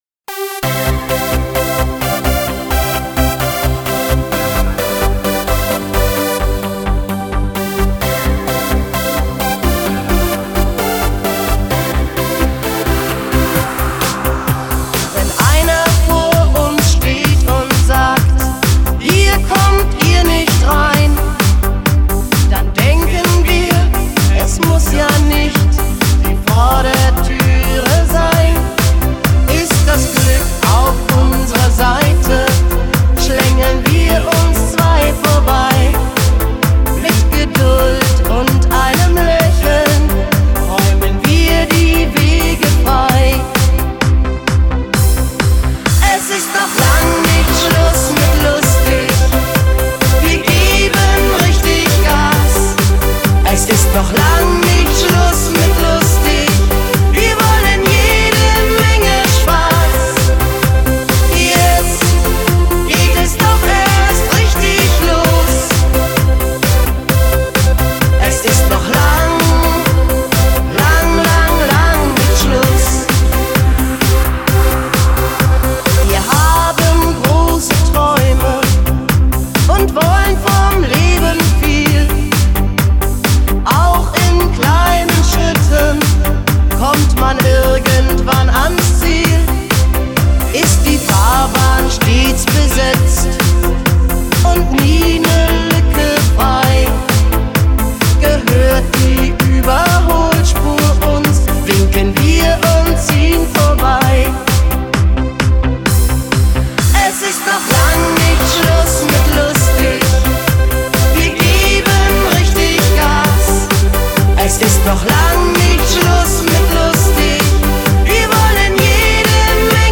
Partyband